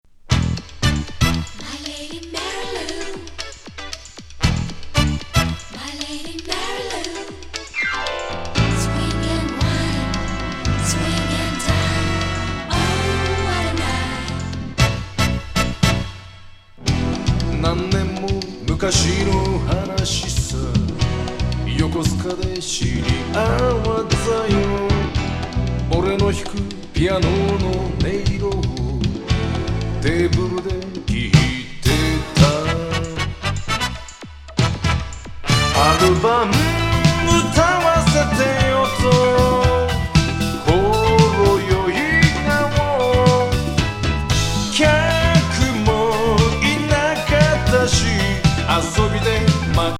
エレクトリック・フュージョン質感のスウィンギン・デカダン・ポップ
City Pop / AOR